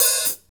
HAT CLEAR 16.wav